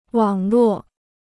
网络 (wǎng luò) Free Chinese Dictionary